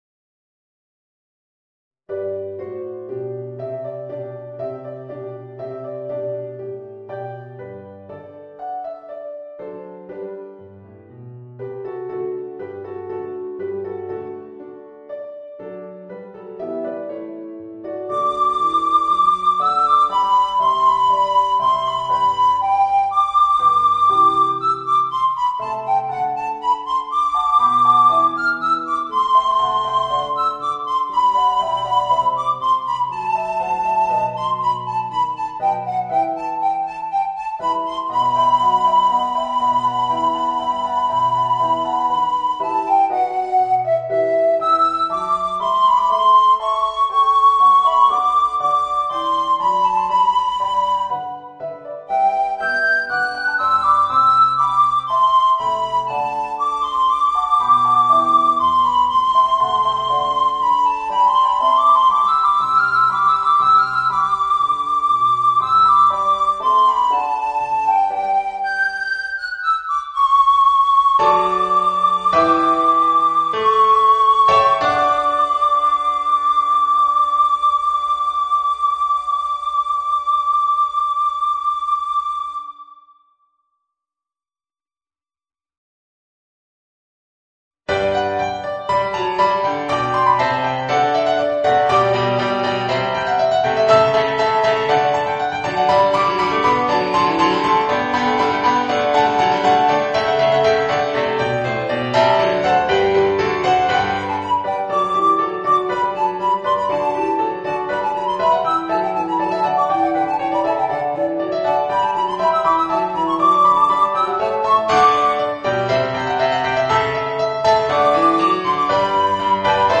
Voicing: Soprano Recorder and Organ